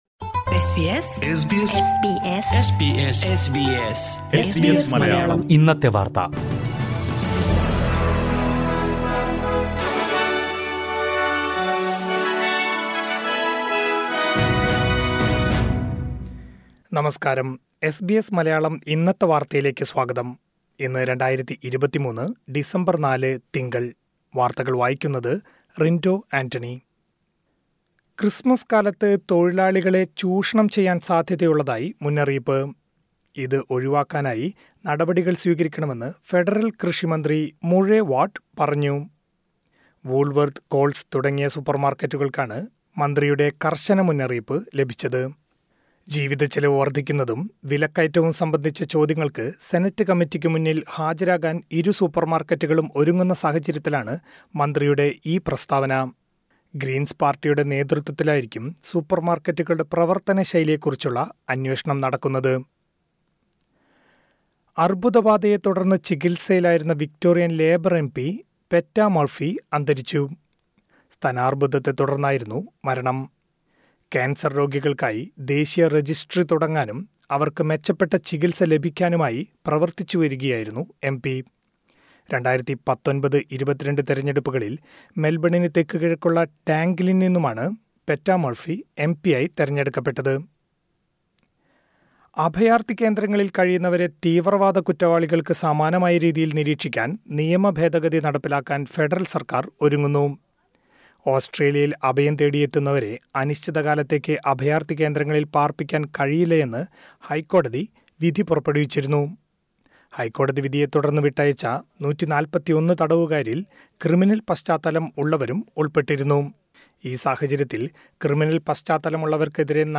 2023 ഡിസംബർ നാലിലെ ഓസ്‌ട്രേലിയയിലെ ഏറ്റവും പ്രധാന വാര്‍ത്തകള്‍ കേള്‍ക്കാം.